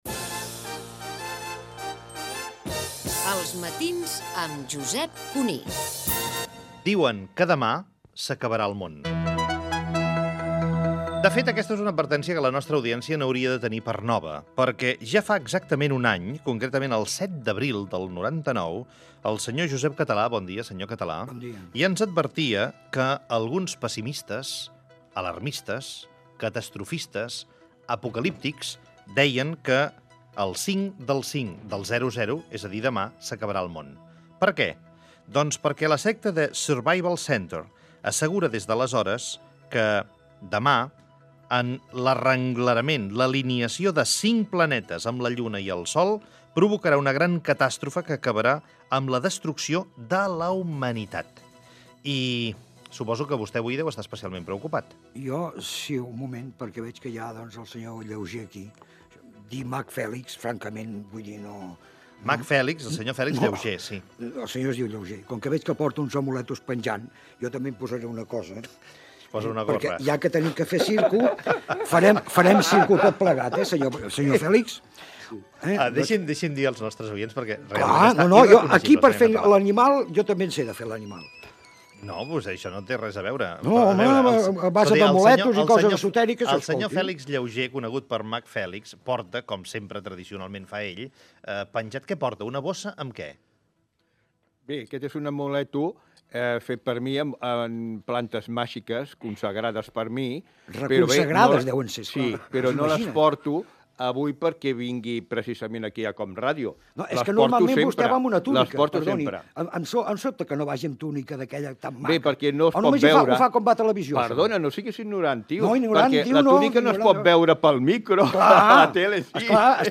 Indicatiu del programa.
El debat s'acaba de forma extremadament bronca.
Info-entreteniment